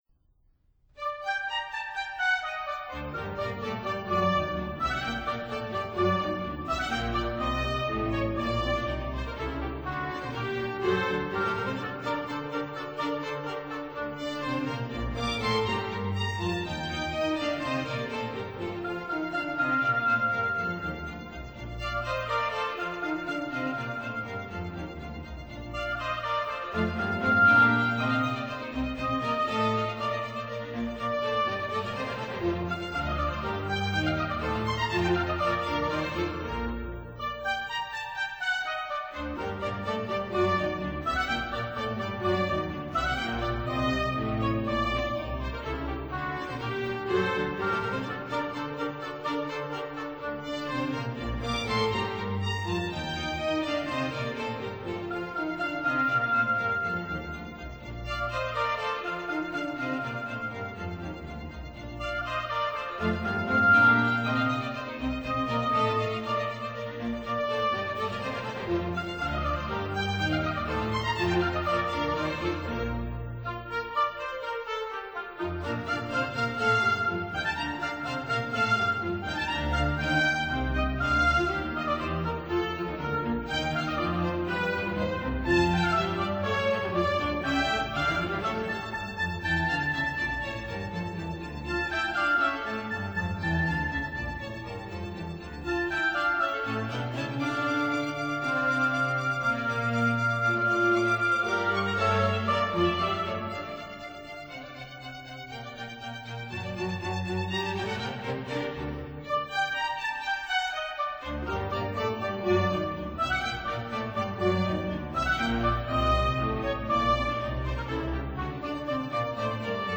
Symphony in A major (Brown A4)